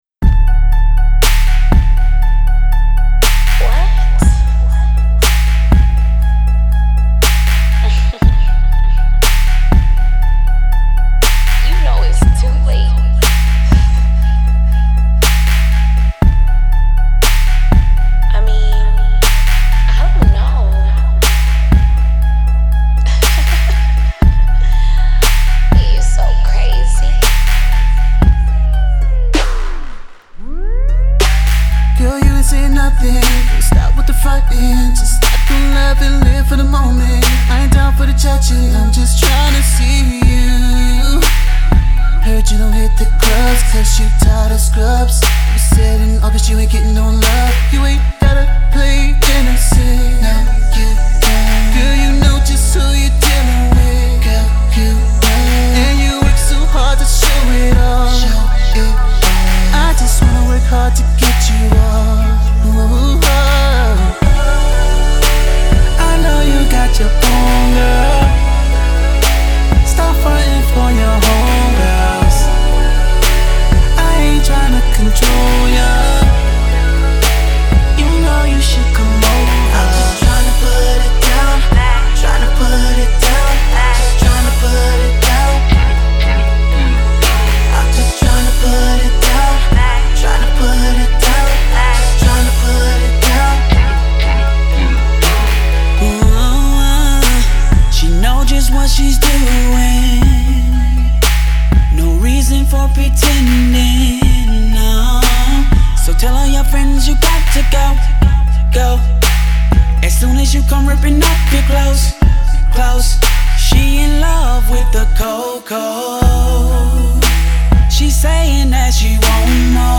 Description : The hottest R&B Group out of Atlanta GA